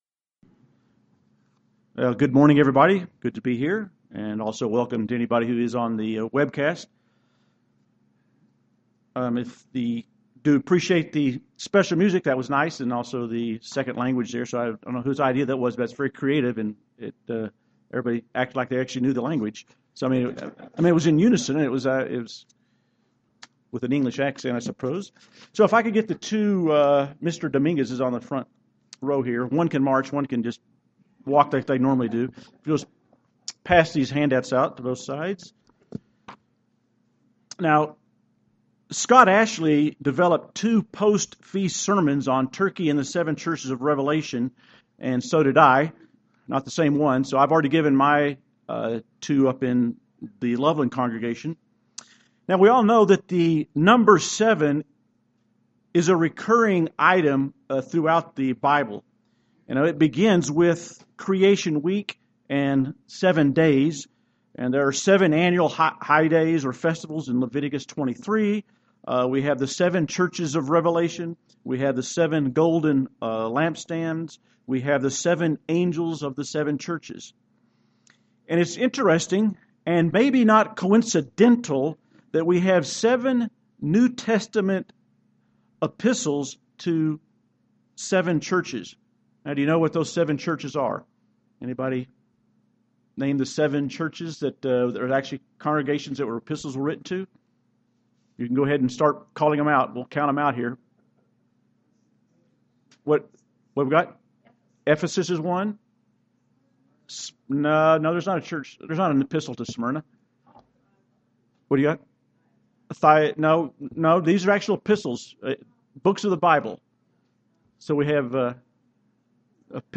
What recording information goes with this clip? Given in Denver, CO